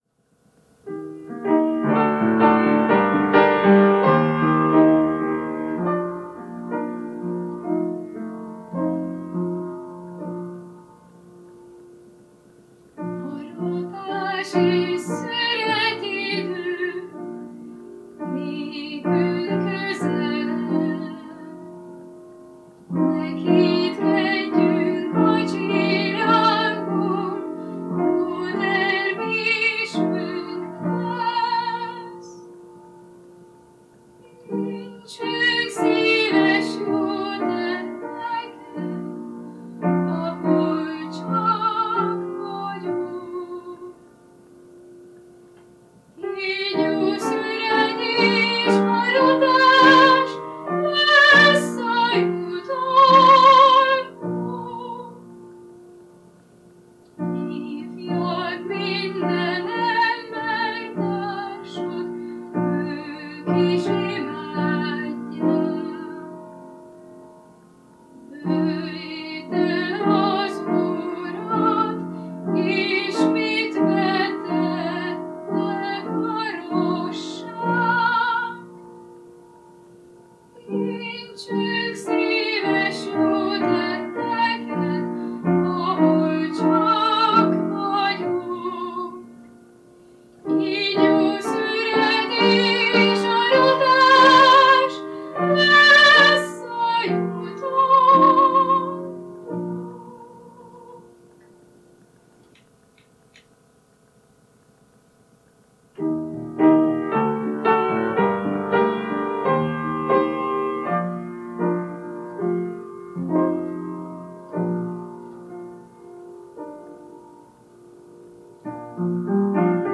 Ének
Zongora